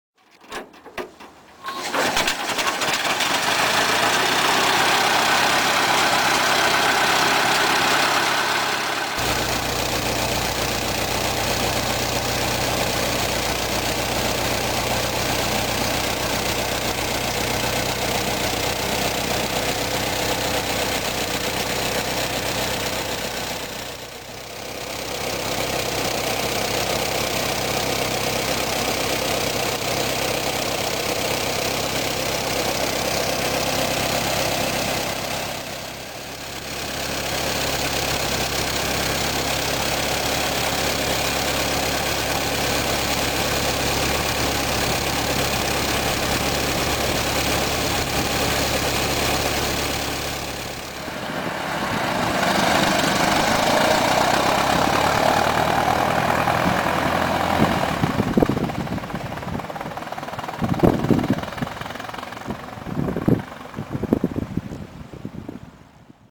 Som do motor Ford T
Ford_model_T_1926_engine.mp3